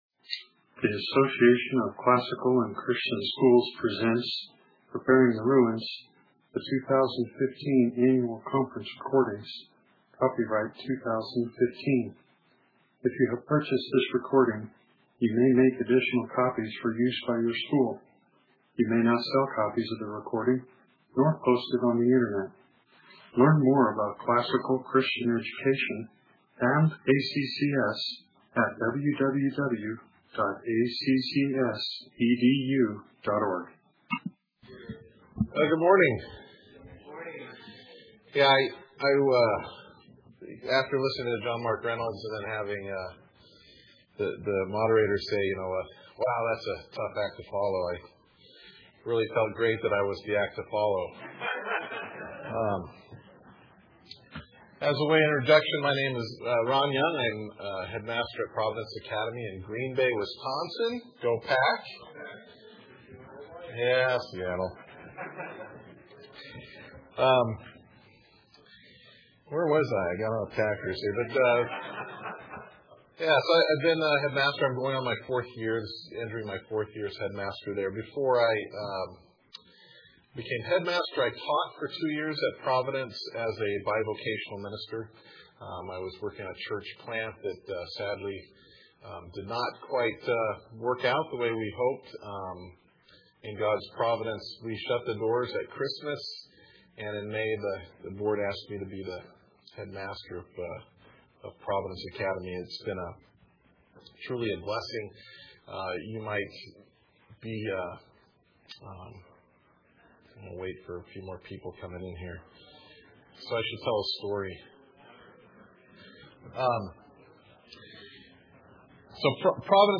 2015 Leaders Day Talk | 0:53:20 | Fundraising & Development, Leadership & Strategic, Marketing & Growth
Speaker Additional Materials The Association of Classical & Christian Schools presents Repairing the Ruins, the ACCS annual conference, copyright ACCS.